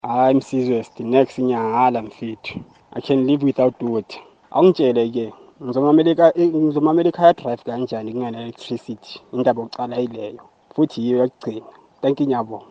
Listen to the Kaya Drive listener’s perspectives: